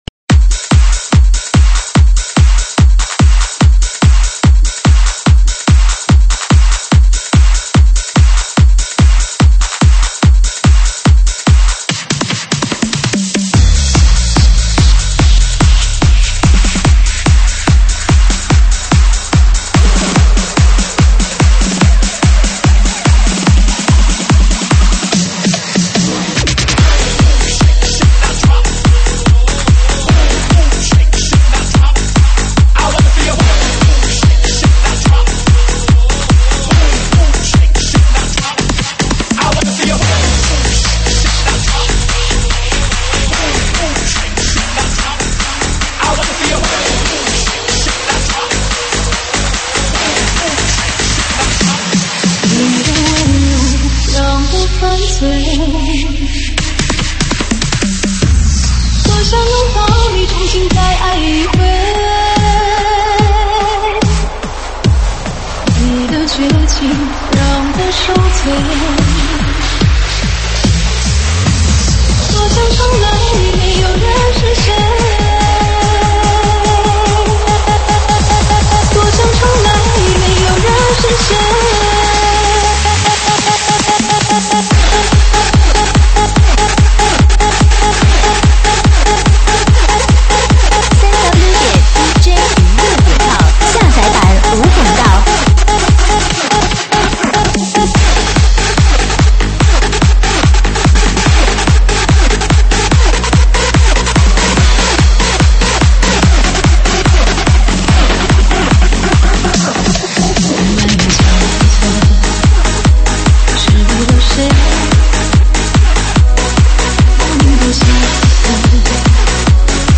中文舞曲
舞曲类别：中文舞曲